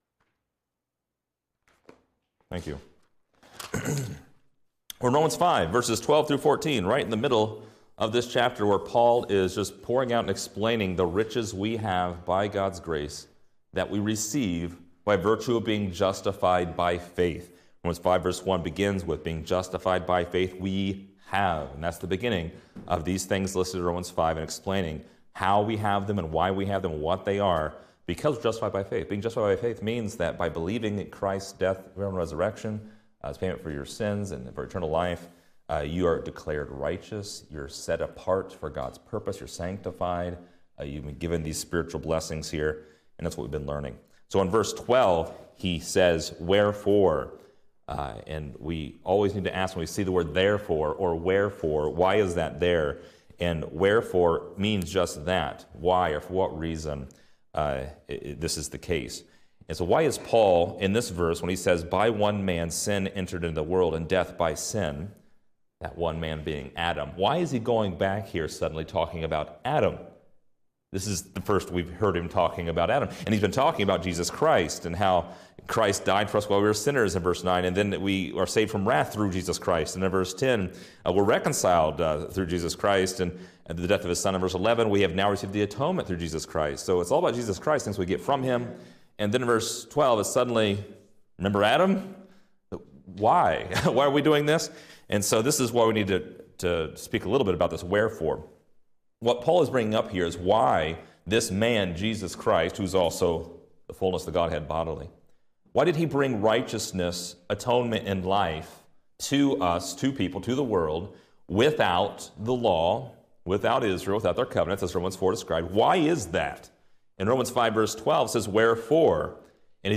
Description: This lesson is part 35 in a verse by verse study through Romans titled: Adam’s Sin.